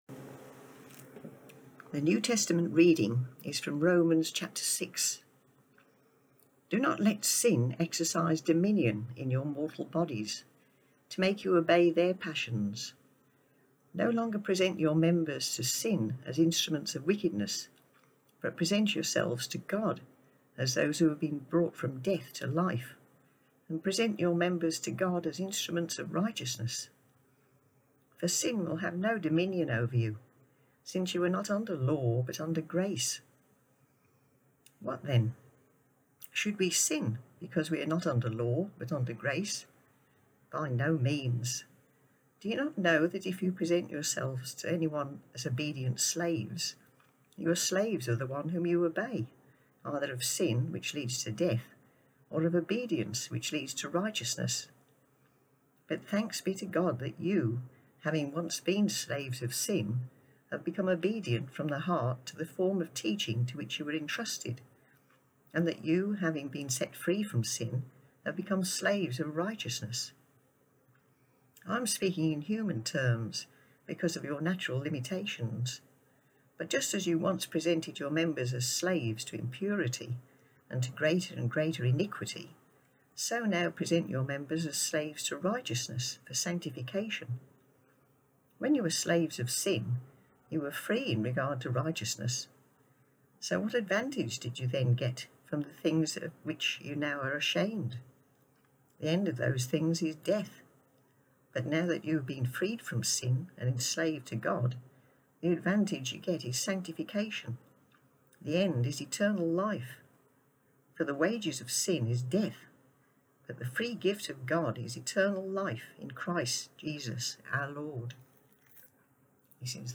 New Testament Reading: